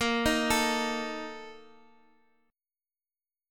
BbM7sus4 chord